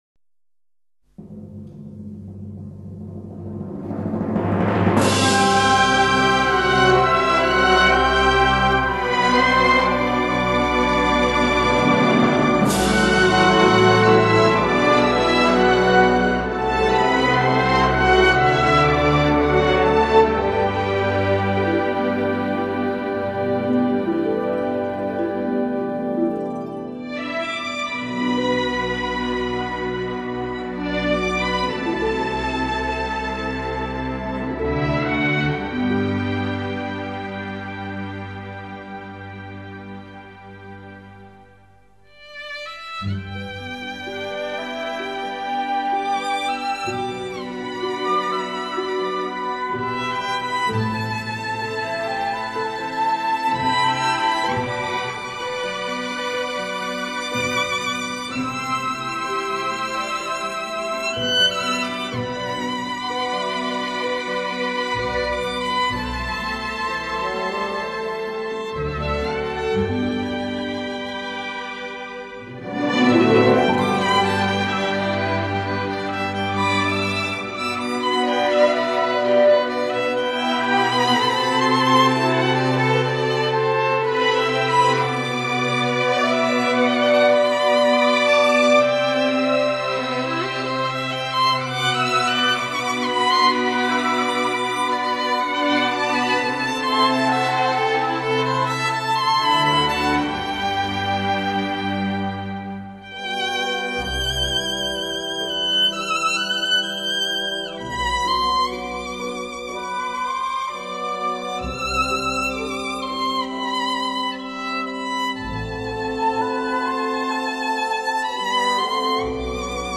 本辑所选之曲目，着意于中国名作和经典篇章，全新配器，旋律刚劲优美，听来如泣如诉，回味无穷。